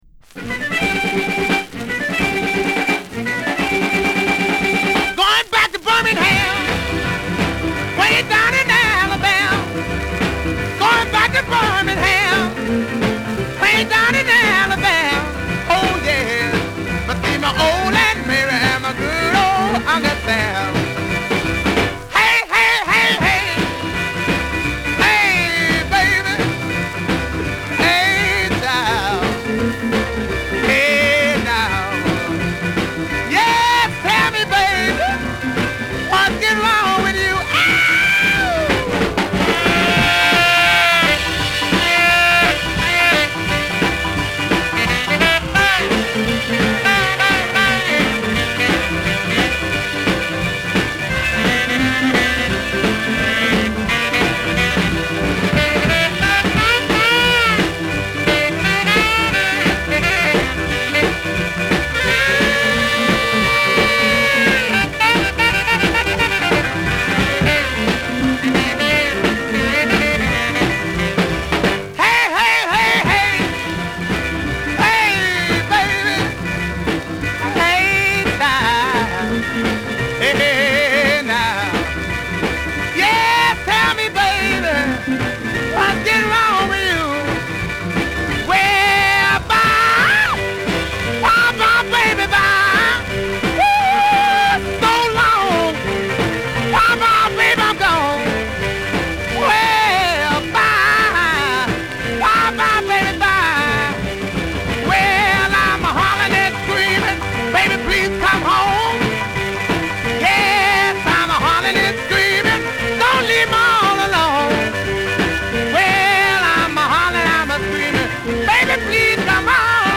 ファーストを遥かに凌ぐけたたましい演奏が脳天に突き刺さる。ブチ切れヴォーカルだけとってみても十分強烈。